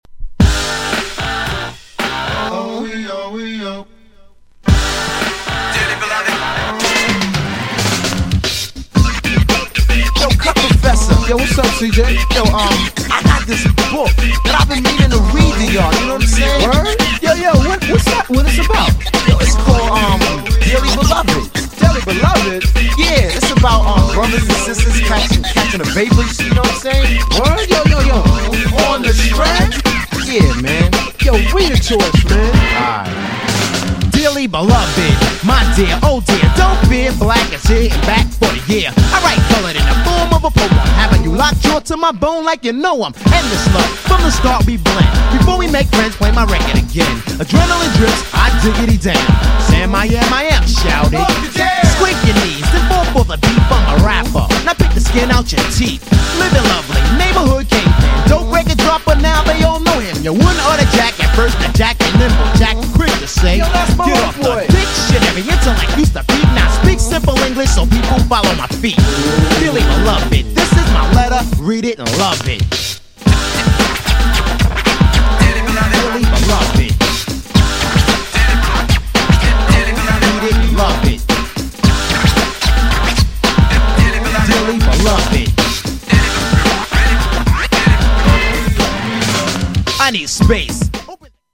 アッパーチューンのB面、両方イイ!!
GENRE Hip Hop
BPM 101〜105BPM